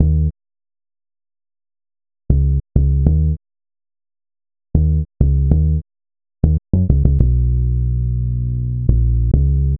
Rock Star - Thick Bass.wav